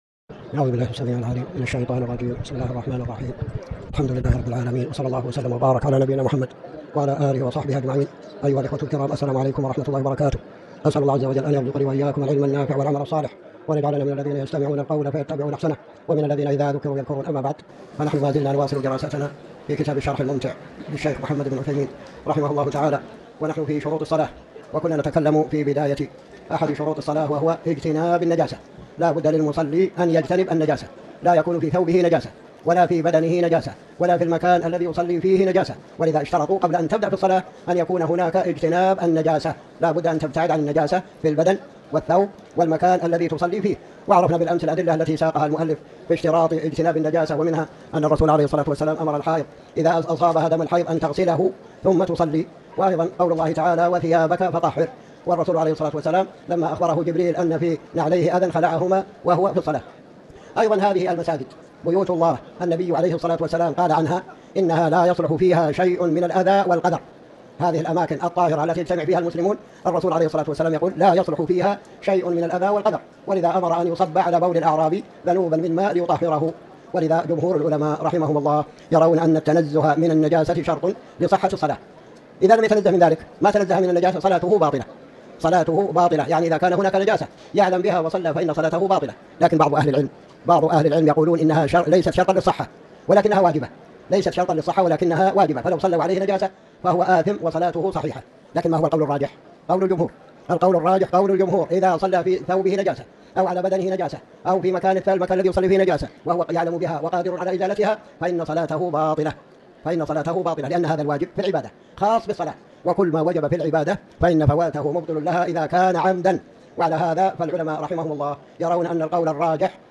تاريخ النشر ١٤ جمادى الآخرة ١٤٤٠ هـ المكان: المسجد الحرام الشيخ